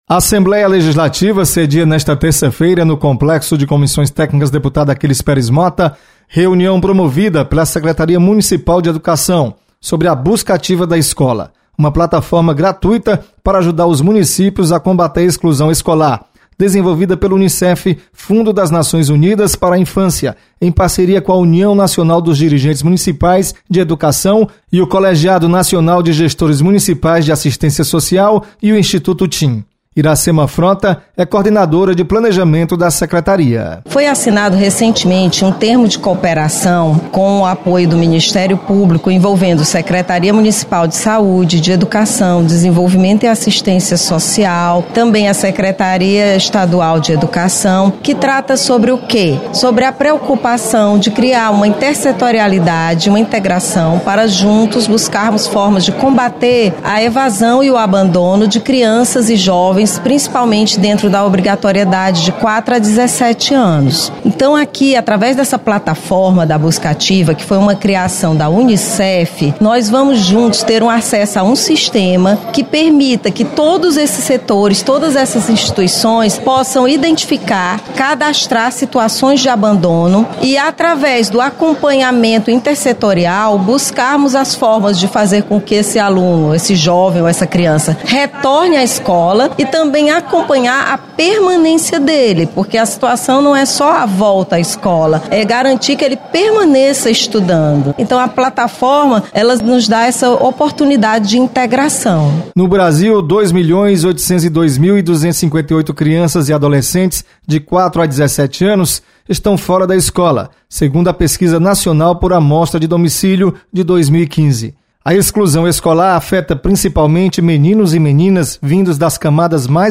Você está aqui: Início Comunicação Rádio FM Assembleia Notícias Reunião